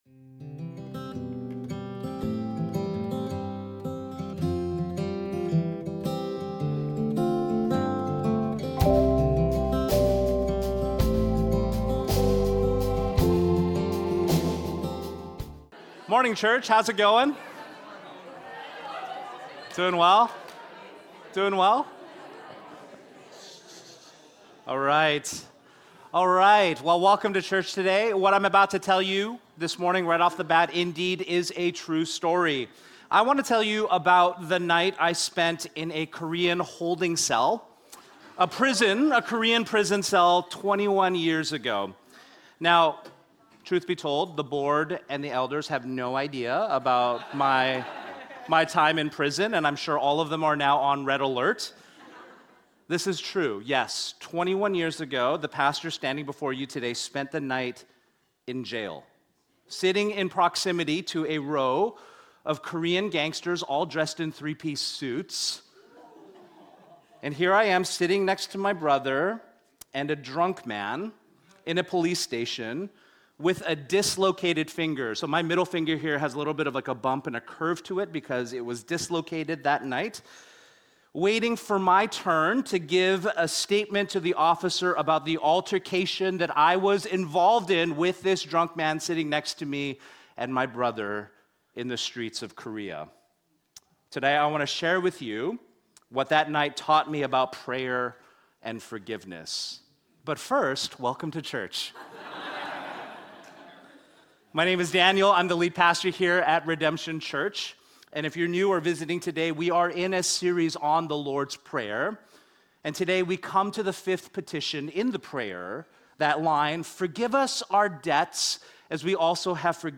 MESSAGES | Redemption Church